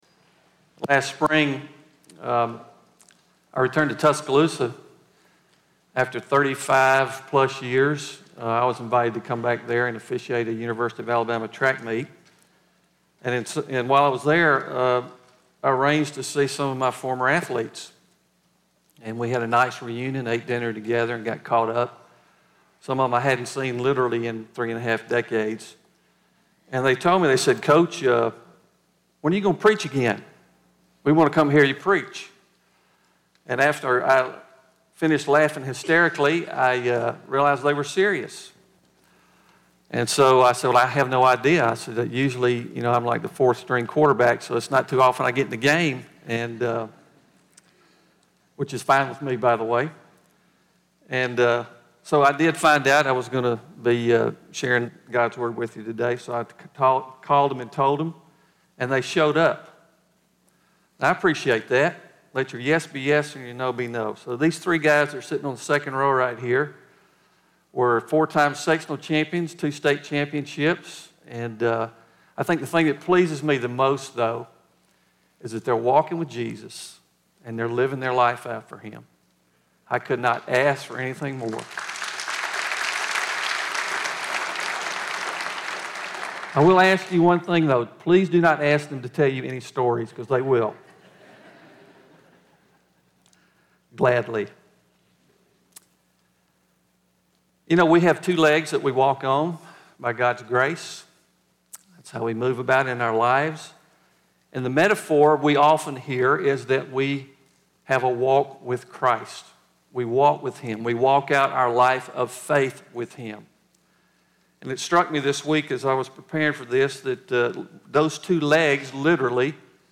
Stand Alone Sermons Service Type: Sunday Morning We must pray to follow and finish our walk with Christ faithfully. 1.